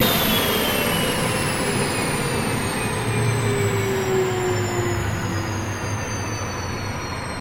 Звуки турбины самолета
Шум падения оборотов турбины